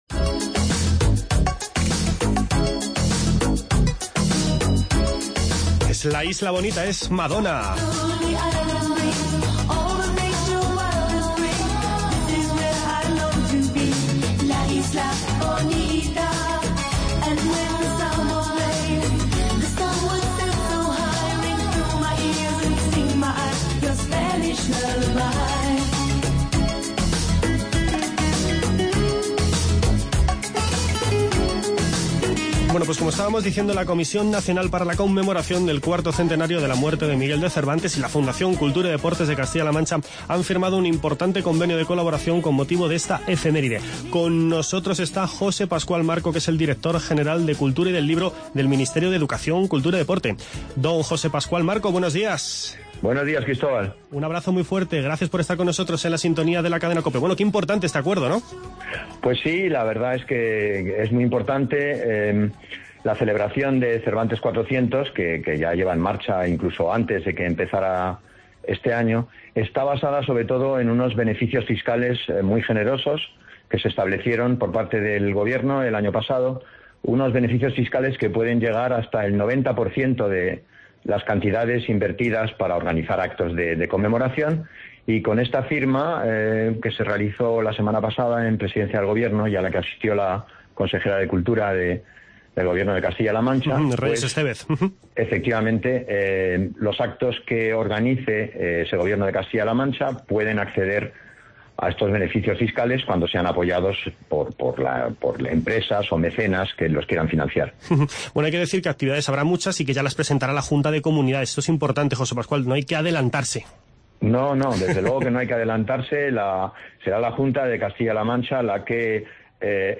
Escuche las entrevistas con José Pascual Marco, director general de Cultura y del Libro del Ministerio de...